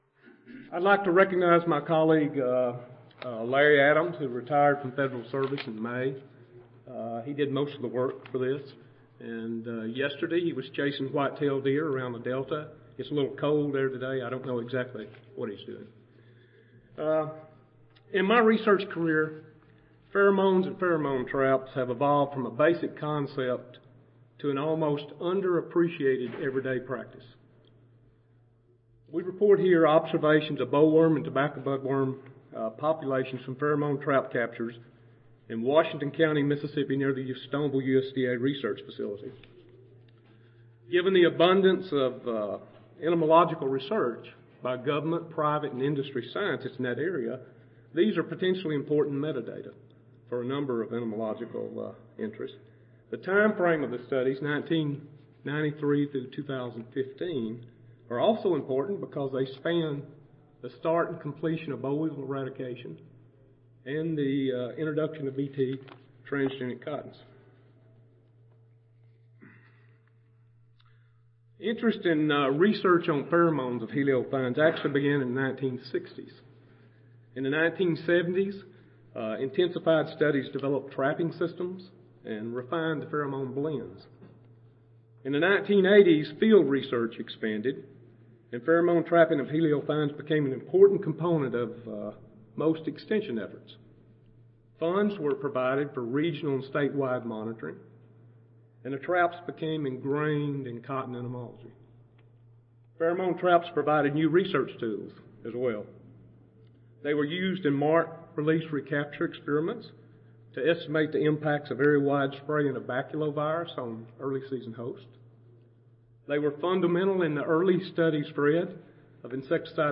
USDA ARS SIMRU Audio File Recorded Presentation Weekly trap captures of Helicoverpa zea and Heliothis virescens moths were collected from five locations around Stoneville, Mississippi for the period 1992 through 2015.